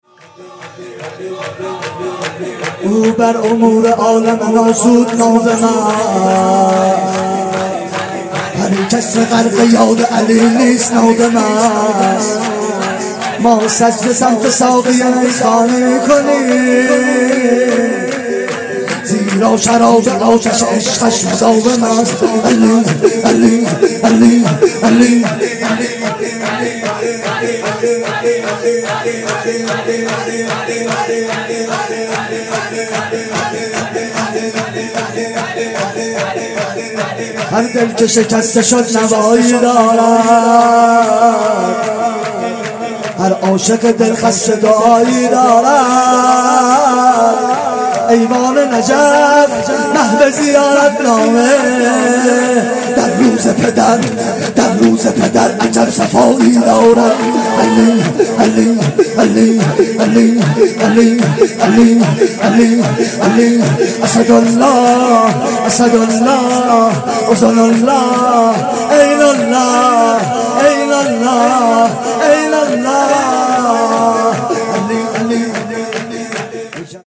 بخش‌پنجم-سورد
هیات ایوان نجف